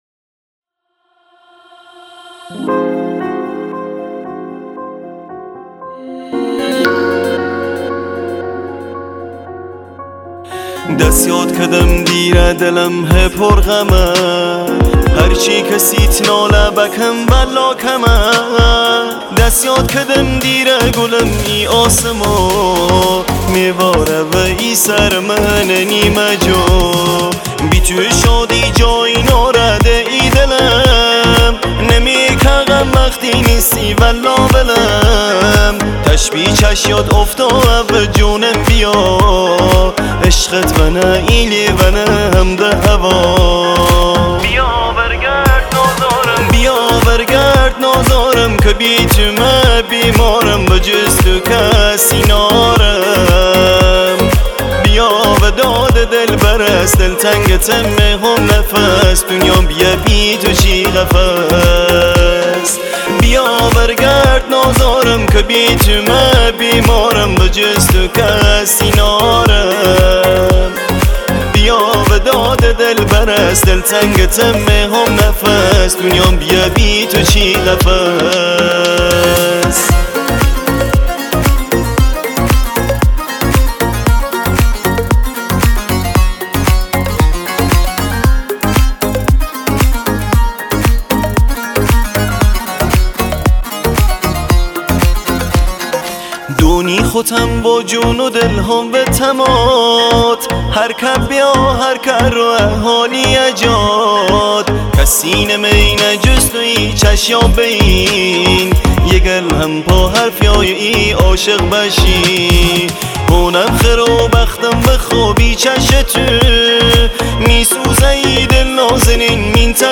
آهنگ لری و لکی